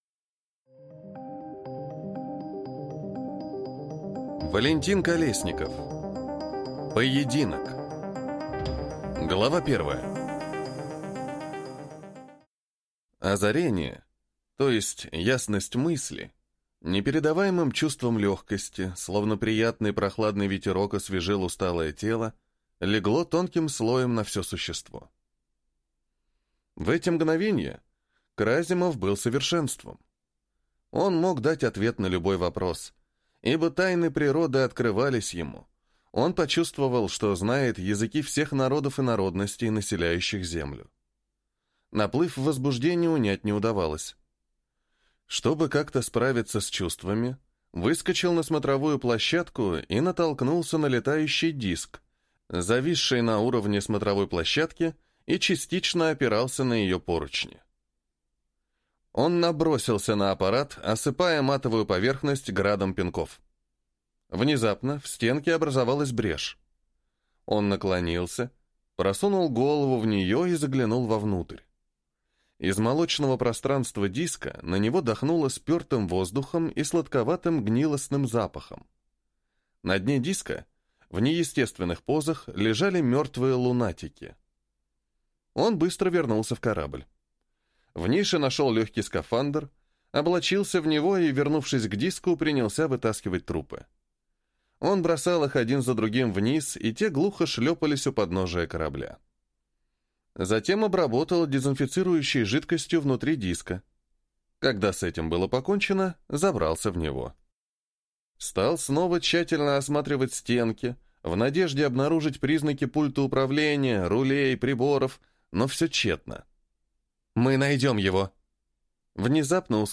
Аудиокнига Поединок | Библиотека аудиокниг